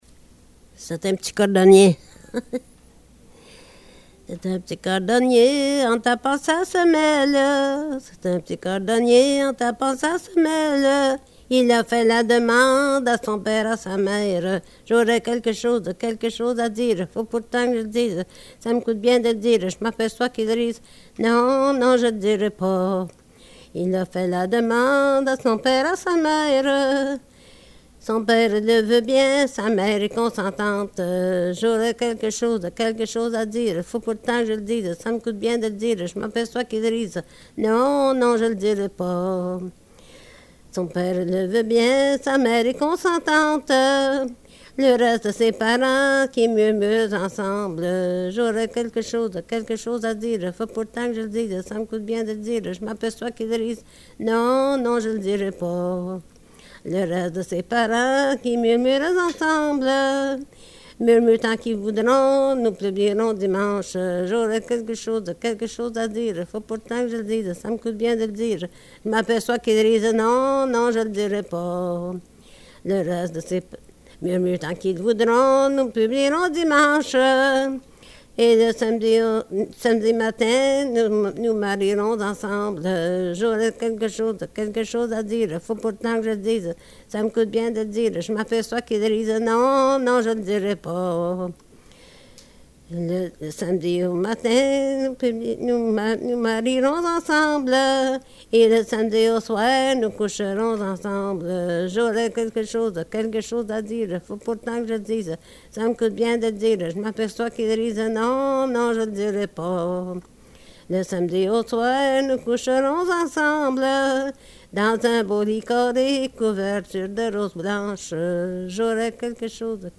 Folk Songs, French--New England
Excerpt from interview